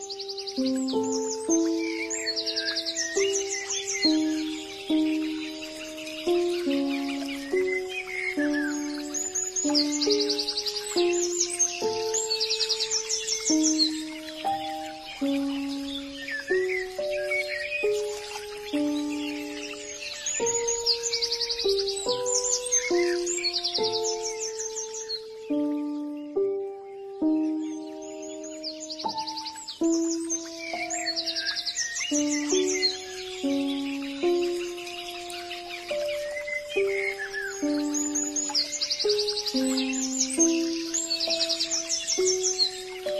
ASMR owl video | satisfying sound effects free download
ASMR owl video | satisfying voice of nature